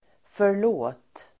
förlåt interjektion (fras för att be om ursäkt; även som artig inledning vid frågor till personer som man inte känner), Sorry!, (I beg your) pardon!Uttal: [för_l'å:t] Definition: ursäkta!